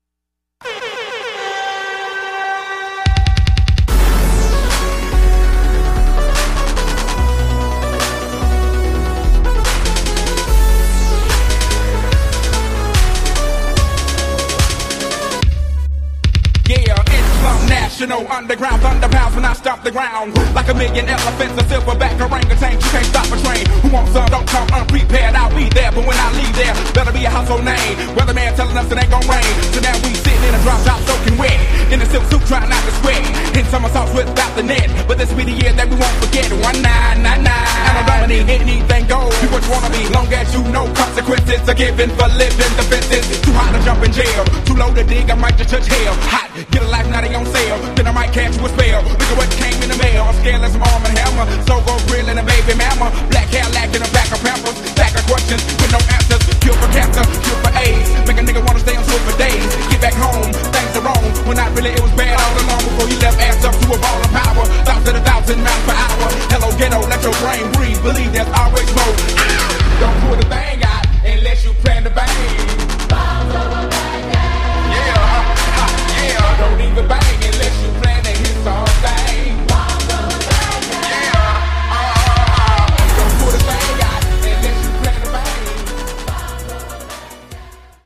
72 bpm
Dirty Version